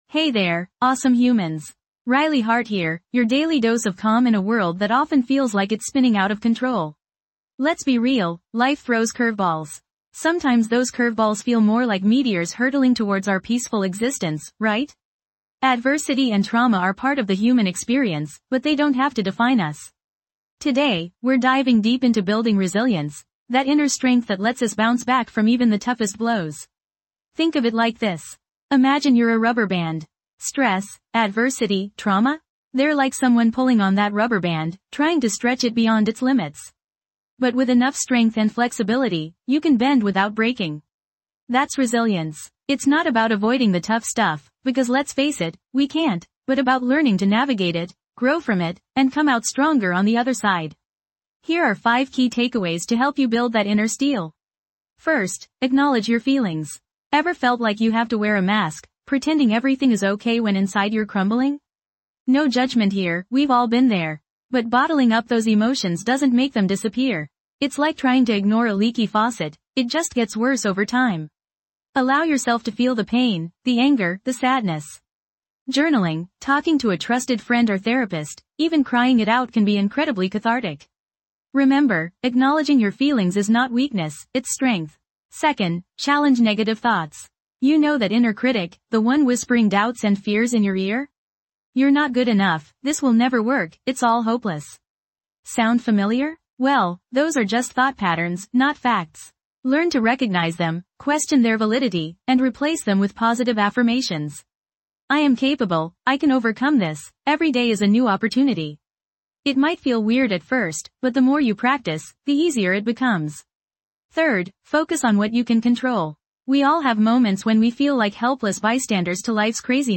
This podcast offers a soothing escape from the daily hustle, providing gentle affirmations and guided meditations designed to calm your mind, release stress, and promote a sense of tranquility. With each episode, you'll discover powerful words that can shift your perspective, quiet racing thoughts, and cultivate a deeper connection with your inner self.